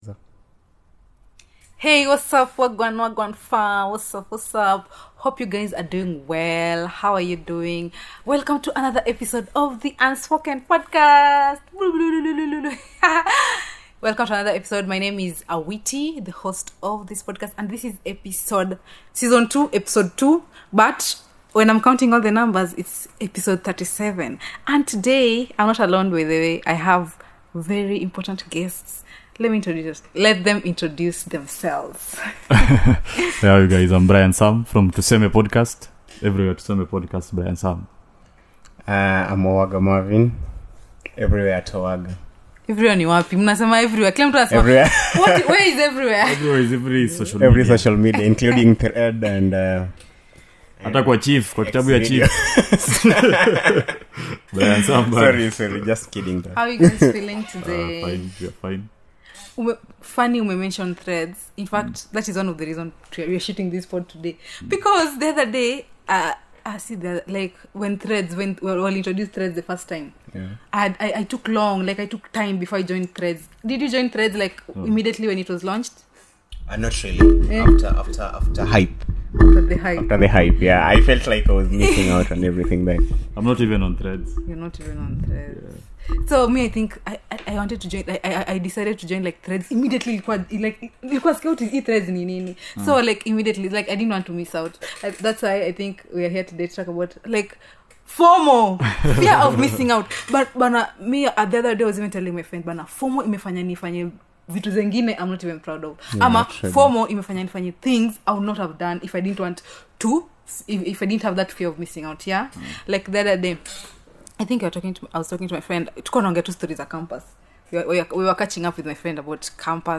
2/2 : In this episode I have a conversation with 2 of my friends and talk about the crazy things FOMO made us do!